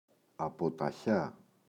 αποταχιά [apota’ça]